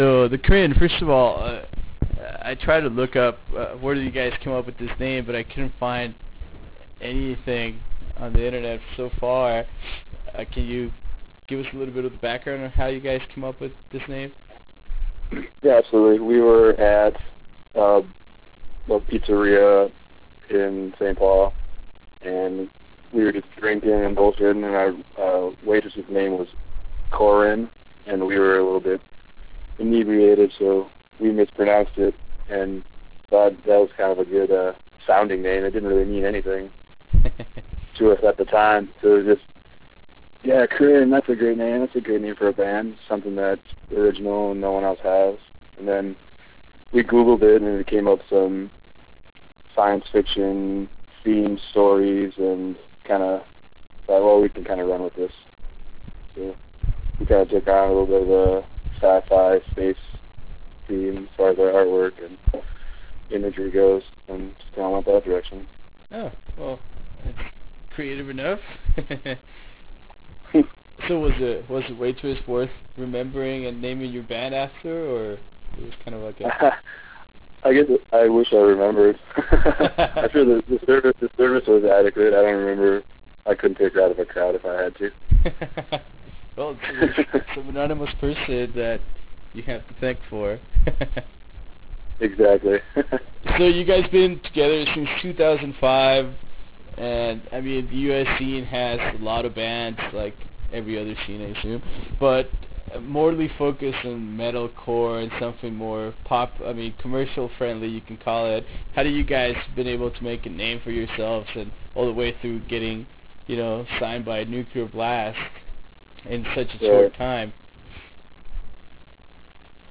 Interview with The Crinn